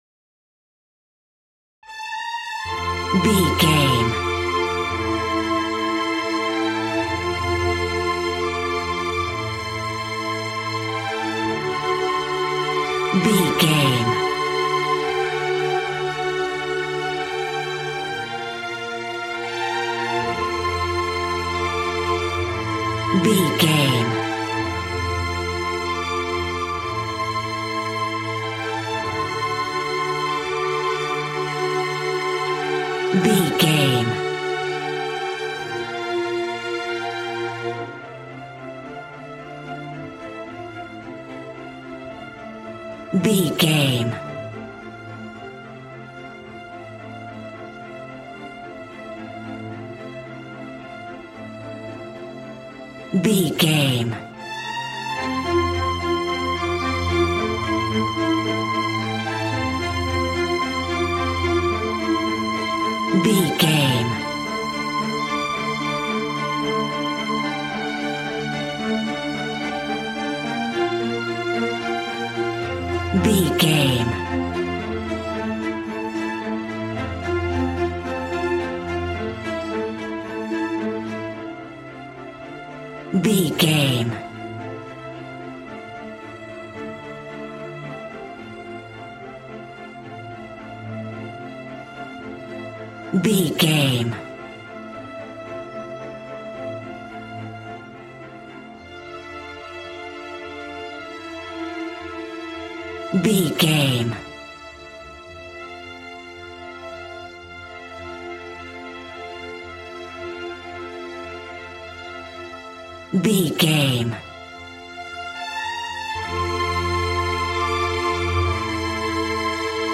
Aeolian/Minor
regal
strings
brass